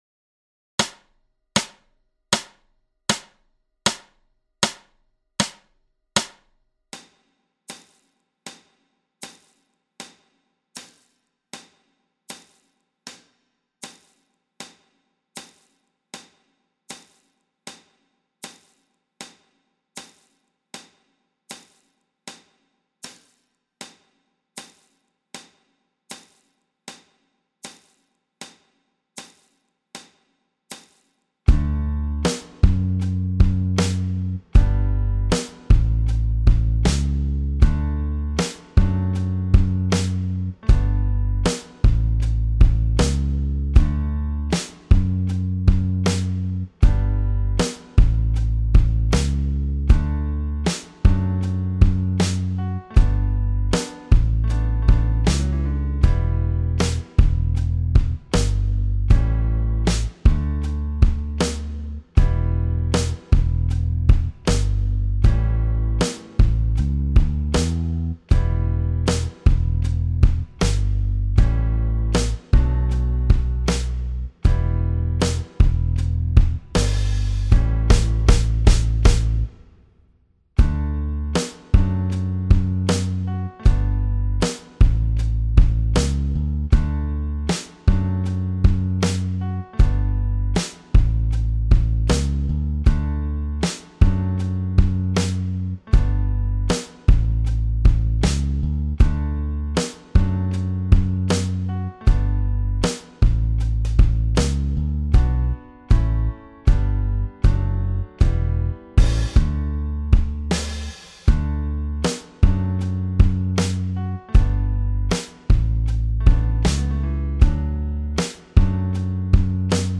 Jam Track No Vocals